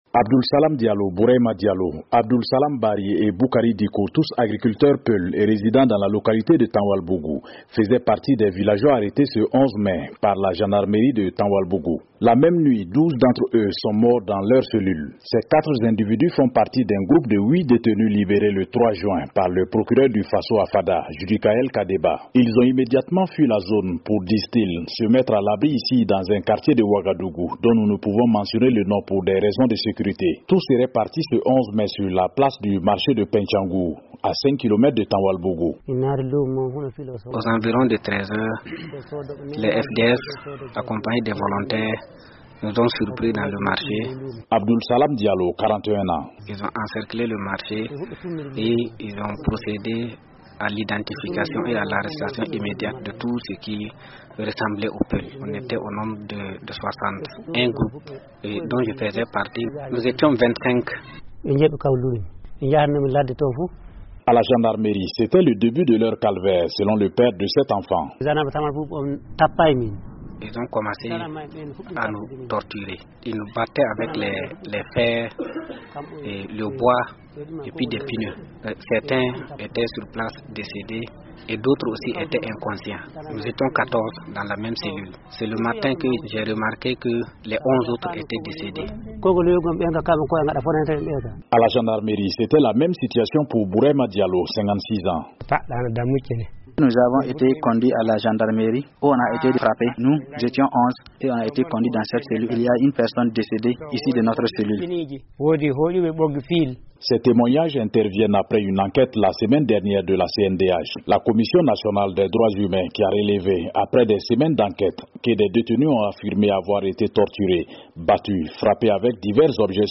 VOA Afrique a rencontré les rescapés de cette tragédie.